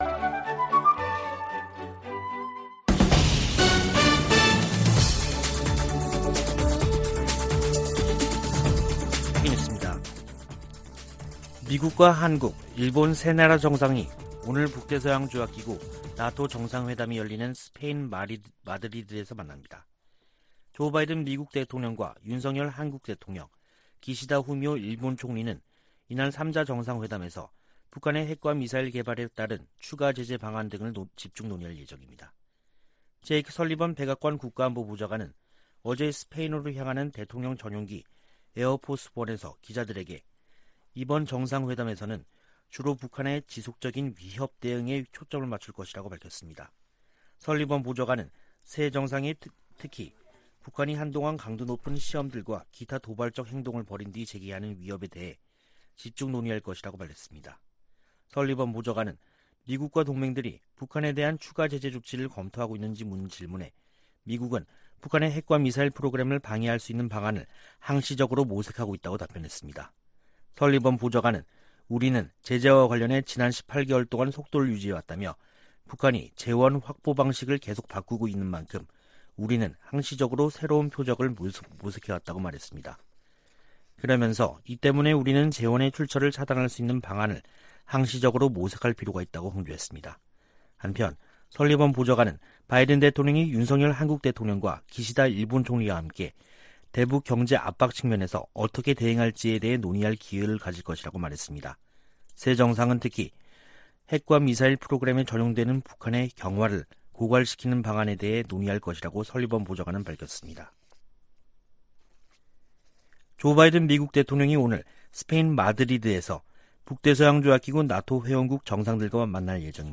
VOA 한국어 간판 뉴스 프로그램 '뉴스 투데이', 2022년 6월 29일 3부 방송입니다. 제이크 설리번 백악관 국가안보보좌관은 나토 정상회의 현장에서 열리는 미한일 정상회담에서 대북 경제 압박 방안이 논의될 것이라고 말했습니다. 옌스 스톨텐베르그 나토 사무총장은 새 전략개념을 제시하면서 중국과 러시아의 도전을 견제하겠다는 의지를 확인했습니다. 한국은 국제 규범과 법치, 인권 문제 등에서 나토에 기여할 수 있다고 미국 전직 관리들이 말했습니다.